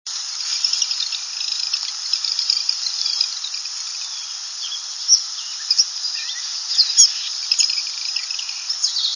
In phragmites along crabbing bridge.
wren_marsh_799.wav